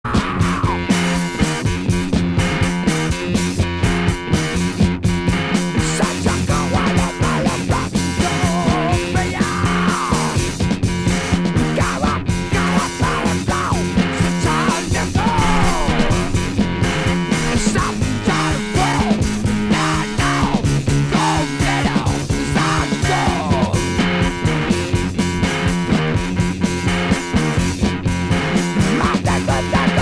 Ovni progressif Unique 45t